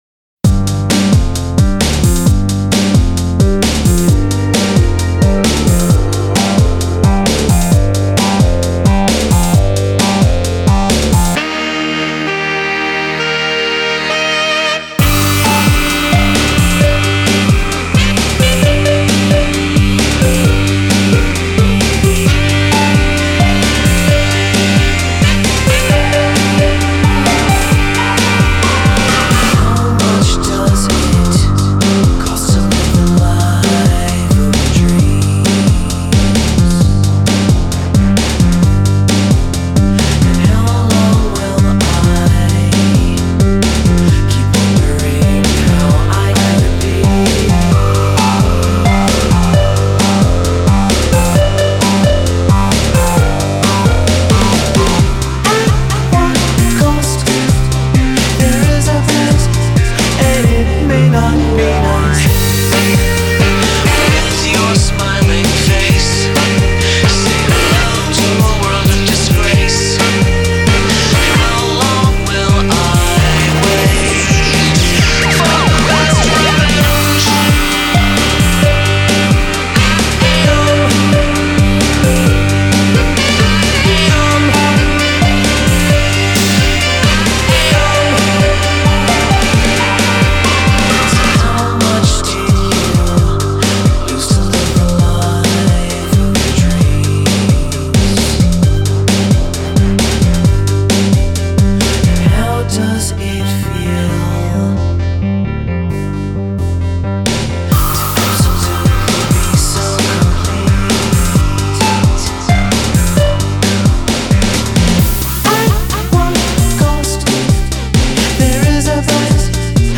electronic pop goodness
with a dash of old soul